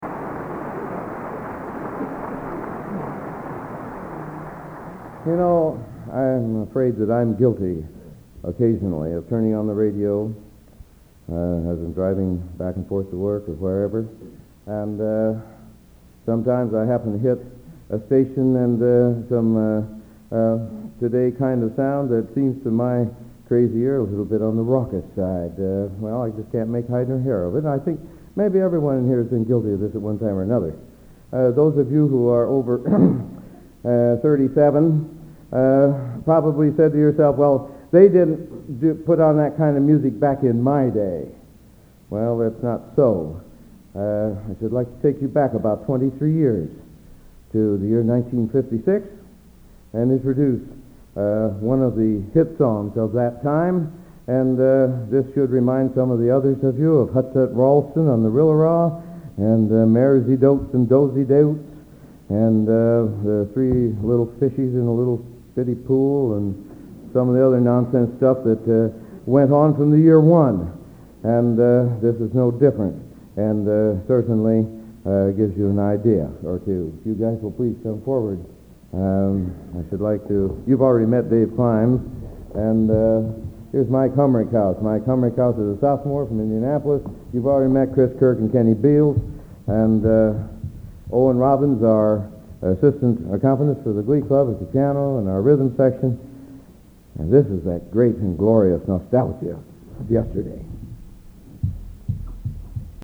Location: West Lafayette, Indiana
Genre: | Type: Director intros, emceeing